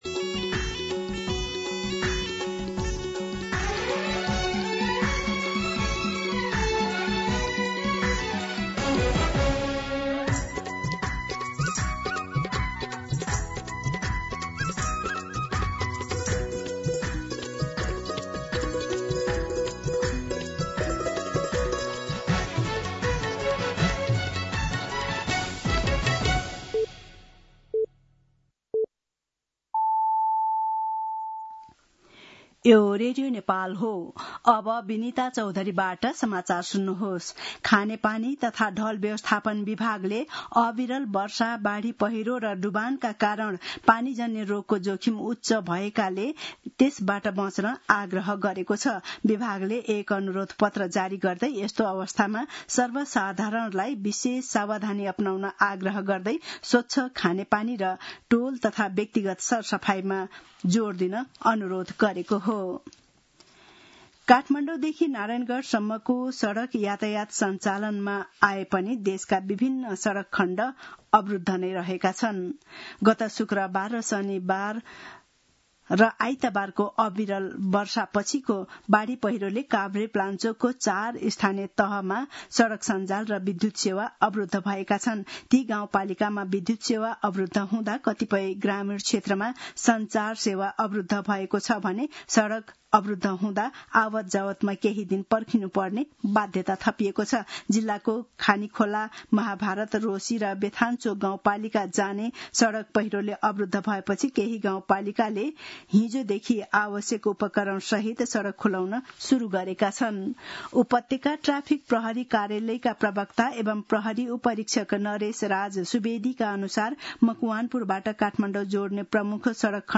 दिउँसो १ बजेको नेपाली समाचार : २६ असार , २०८२
1pm-News-06-21.mp3